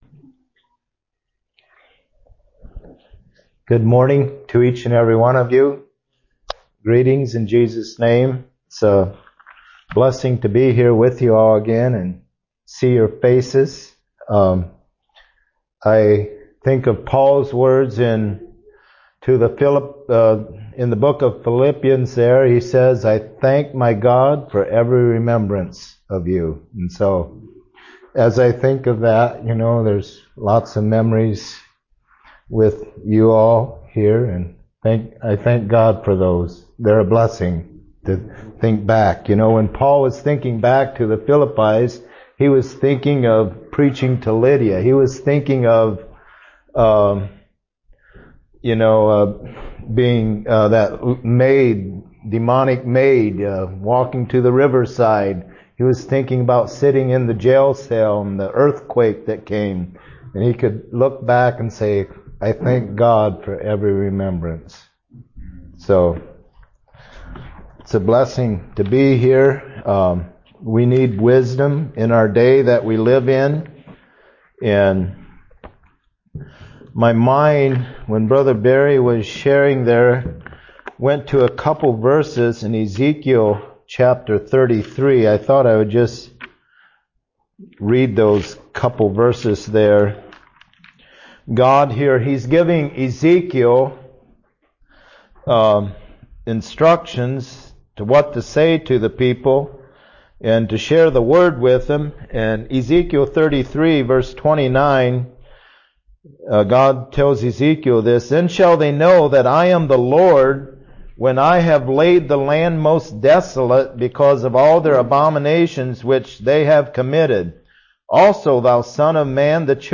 Listen to and download sermons preached in 2025 from Shelbyville Christian Fellowship.